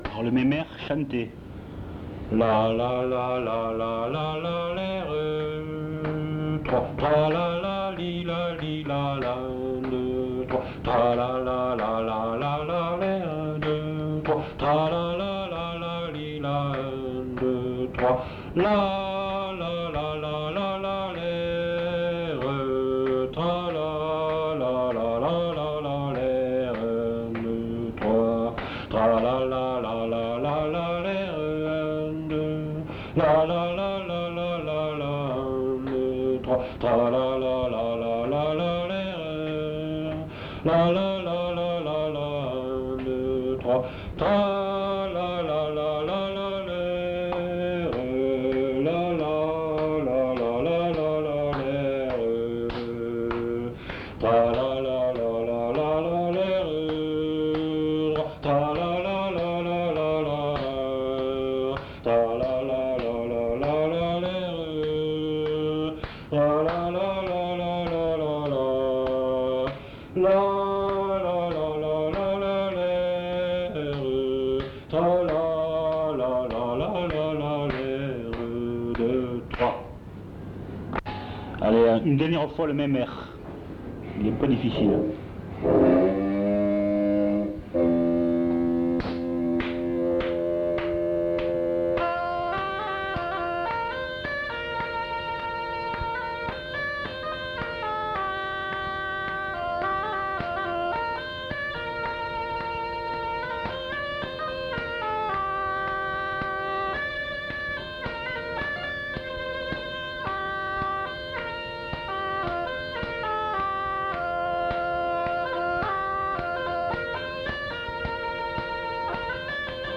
Airs de chansons et de danses interprétés à la bodega
enquêtes sonores
version lente